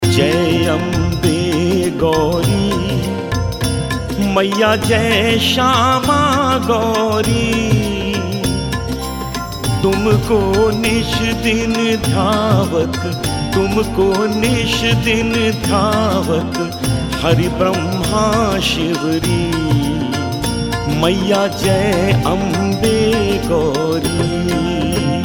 Devotional Ringtones